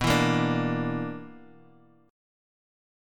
BmM7bb5 chord